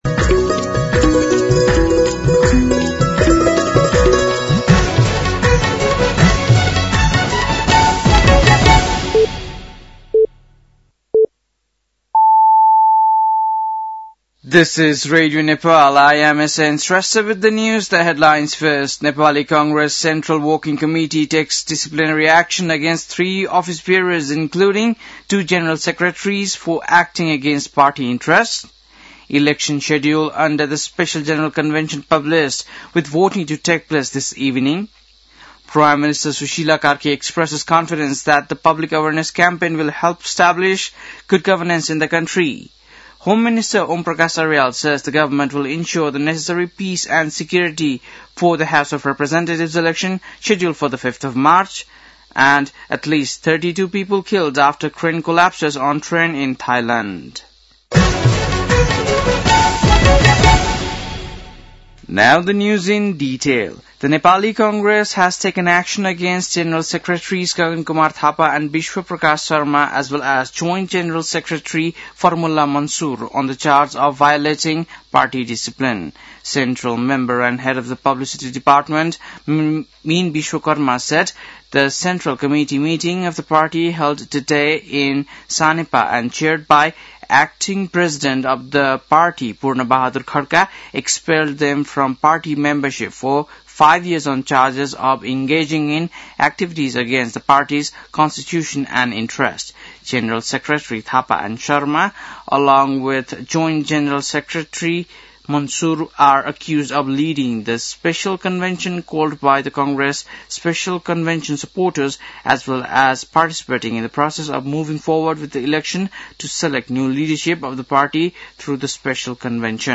बेलुकी ८ बजेको अङ्ग्रेजी समाचार : ३० पुष , २०८२
8-pm-news-9-30.mp3